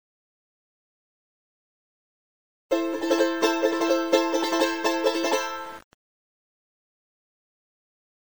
ポトシ地方のチャランゴ奏法 〜 右手の弾き方 〜
曲の区切りのいい部分などでは、こんな弾き方をすると雰囲気が出ます。
（ロ）□　↑↓↑↓　　↑↓↑↓　□